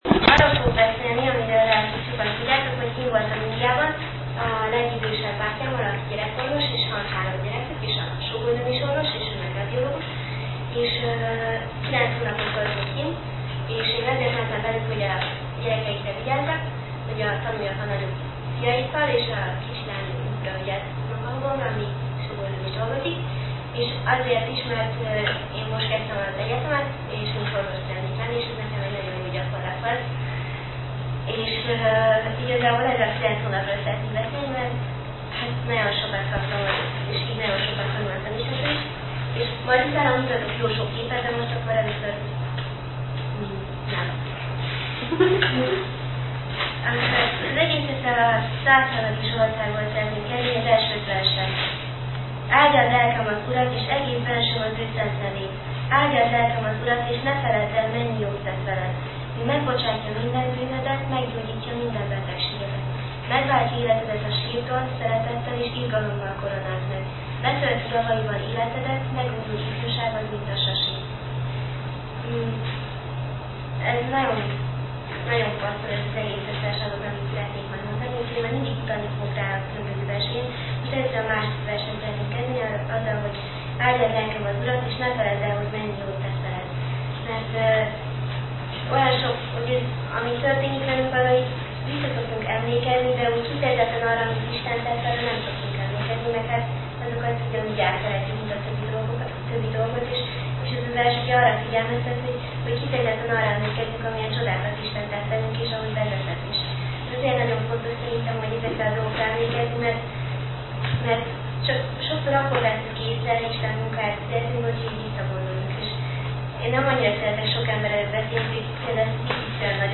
Bizonyságtétel és indiai beszámoló